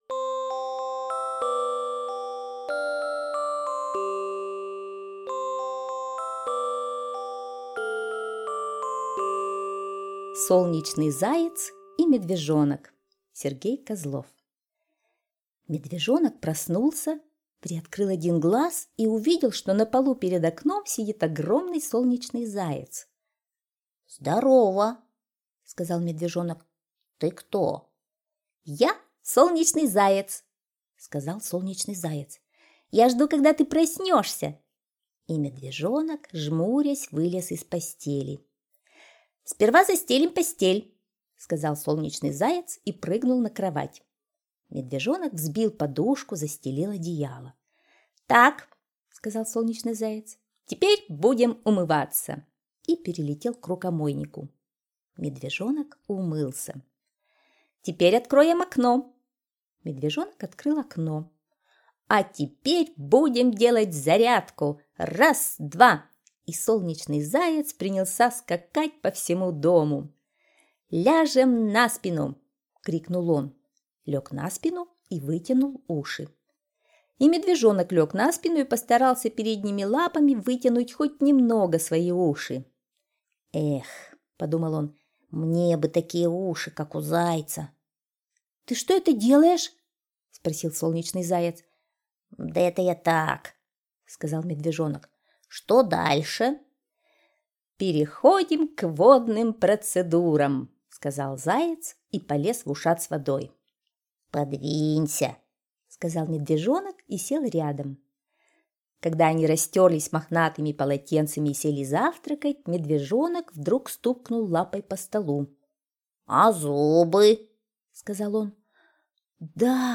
Слушайте Солнечный заяц и Медвежонок - аудиосказка Козлова С.Г. Сказка про то, как Солнечный Заяц пришел утром в гости к Медвежонку .